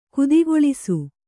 ♪ kudigoḷisu